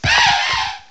cry_not_cufant.aif